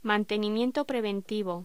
Locución: Mantenimiento preventivo
voz